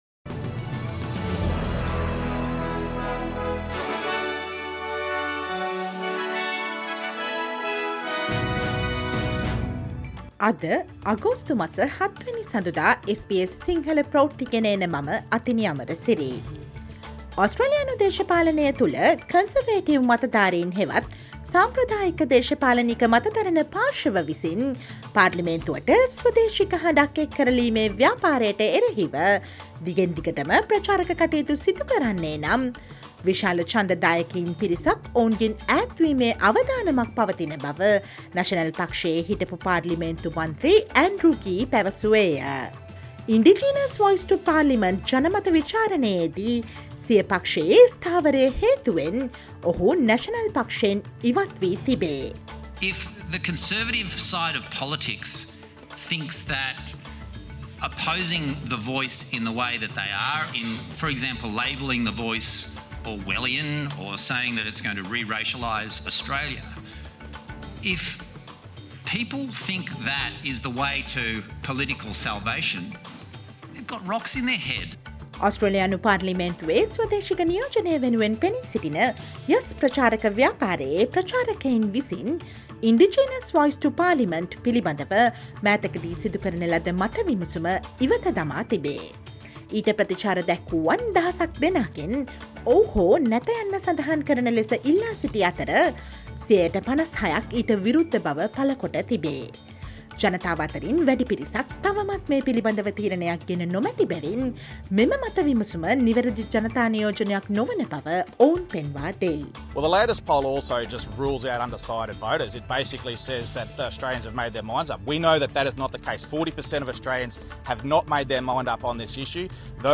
Listen to the news highlights of Australia, around the world and in sports from SBS Sinhala News Flash today, Monday, 7 August 2023